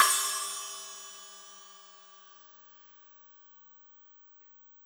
Cymbol Shard 05.wav